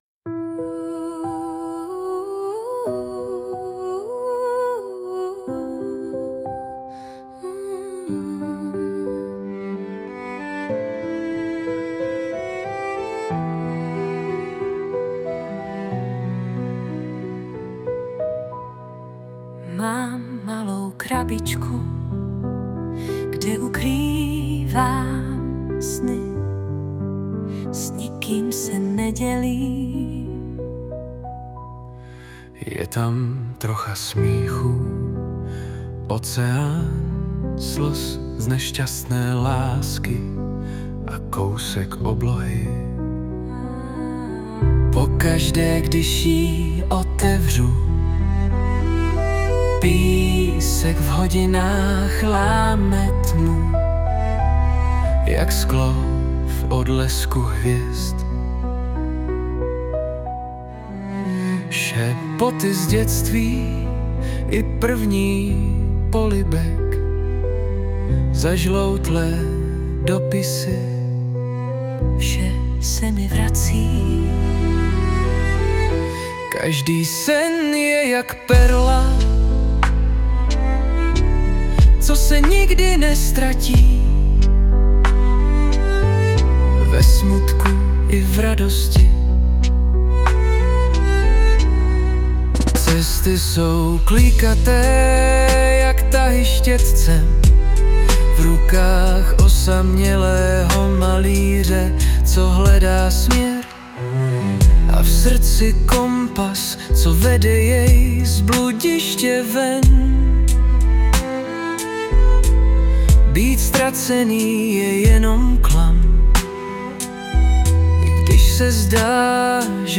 2025 & Hudba, zpěv a obrázek: AI